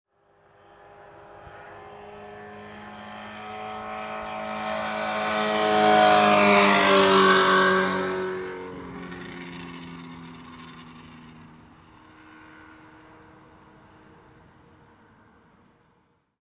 Download Motorbike sound effect for free.
Motorbike